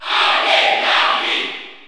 Category: Bowser Jr. (SSBU) Category: Crowd cheers (SSBU) You cannot overwrite this file.
Larry_Cheer_French_PAL_SSBU.ogg